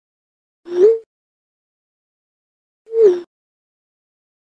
iPhone Text Message Sound Effect MP3 Download Free - Quick Sounds
iPhone Text Message Sound